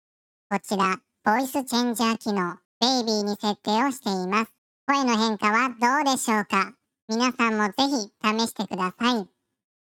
ボイスチェンジャー
ボタンひとつで声質を変化させてくれます。
■ Baby（赤ちゃん）